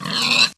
pigdeath.ogg